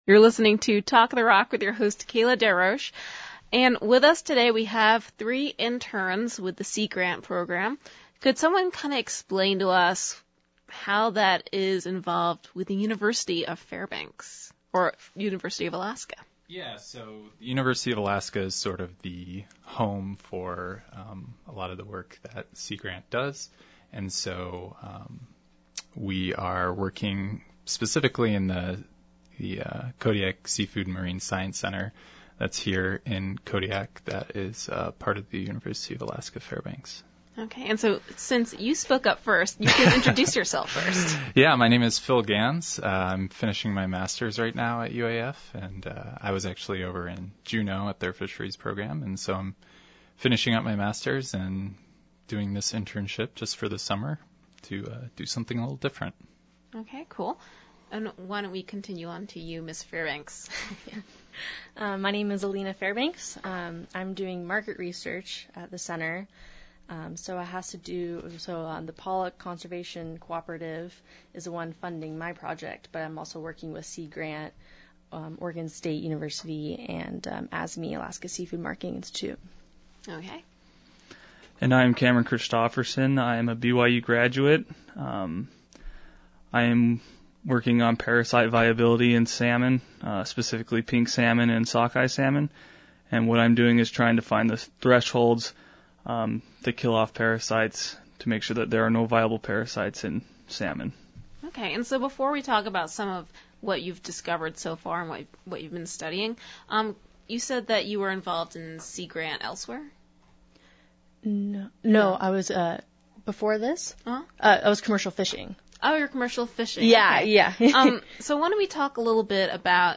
On today’s Talk of the Rock, three summer interns with the Alaska Seafood Marketing Institute join to speak about their studies so far, from bacteria in fish to powdered health supplements.